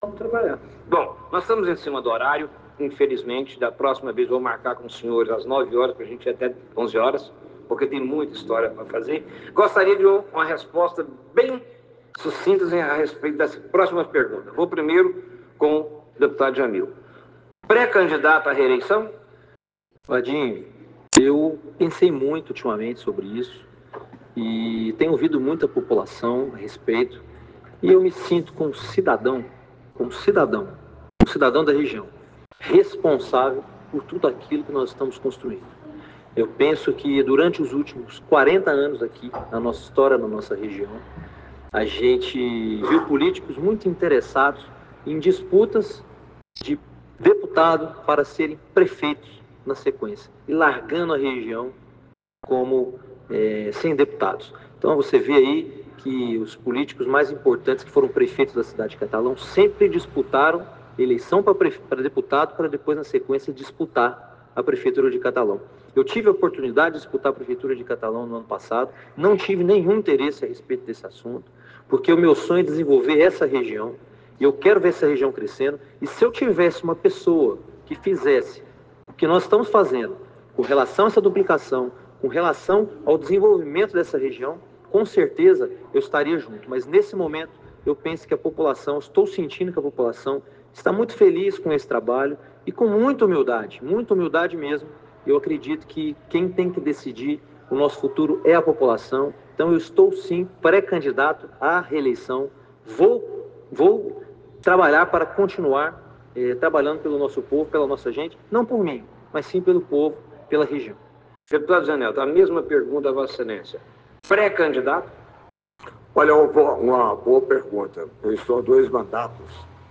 Em entrevista às rádios de Ipameri, o deputado Jamil Calife (UP), acompanhado pelo prefeito Jânio Pacheco e pelo deputado federal José Nelto, confirmou sua pré-candidatura à reeleição.
Ao mesmo tempo, em Ipameri, o deputado estadual Jamil Calife concedia entrevistas nas duas emissoras da cidade, Rádio Fênix e Vale FM,  acompanhado do prefeito Jânio Pacheco e do deputado federal José Nelto.